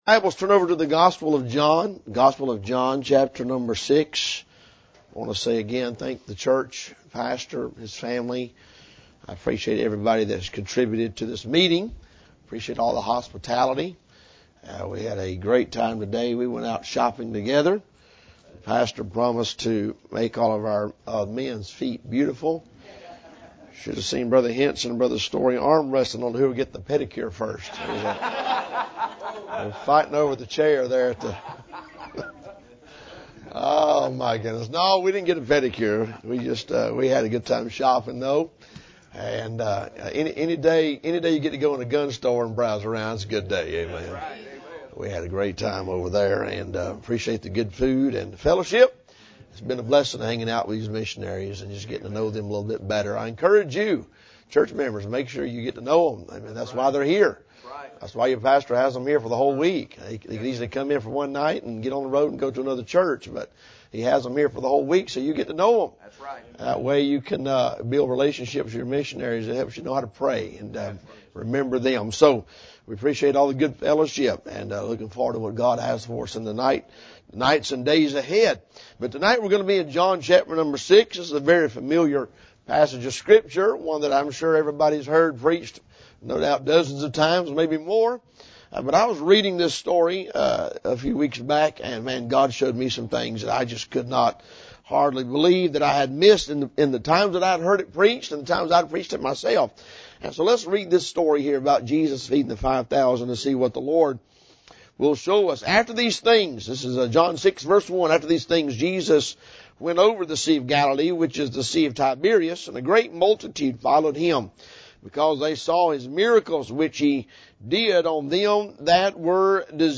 Missions Conference